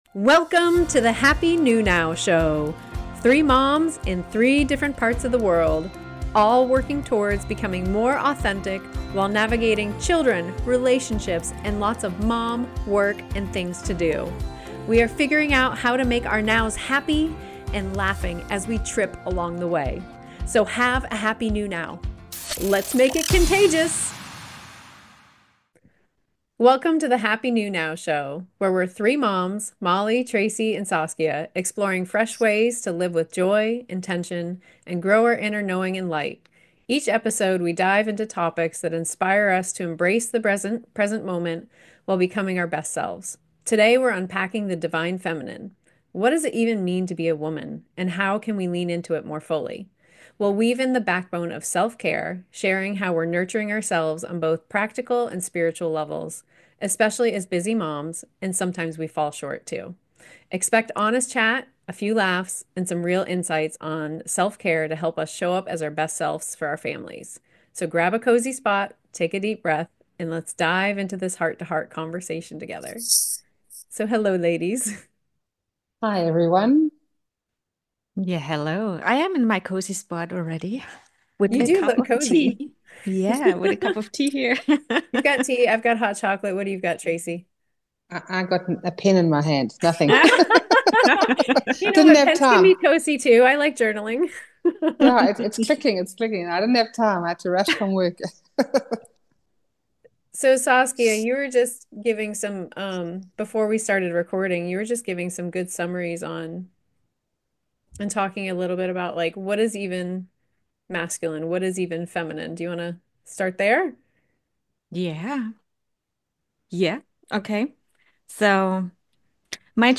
Talk Show
3 Moms, in 3 different parts of the world.
Each episode brings you uplifting conversations, actionable tips, and fresh perspectives to help you embrace the present while building a brighter future.